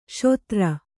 ♪ śotra